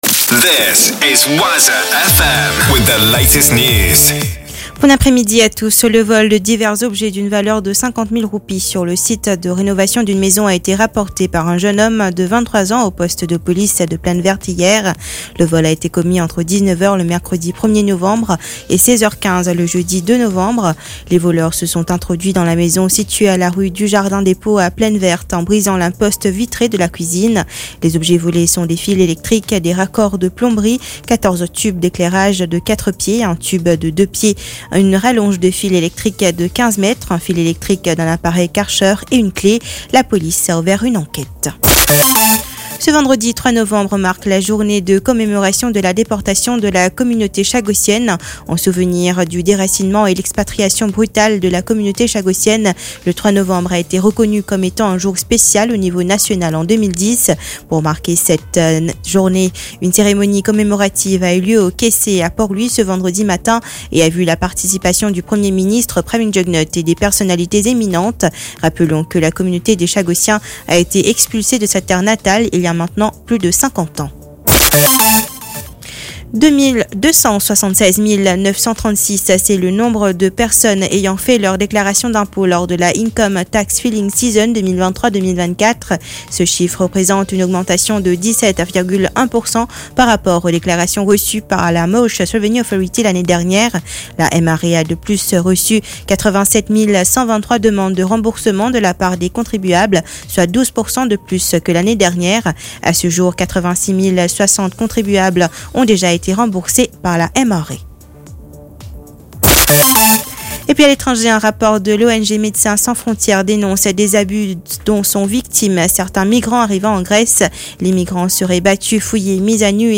NEWS 15H - 3.11.23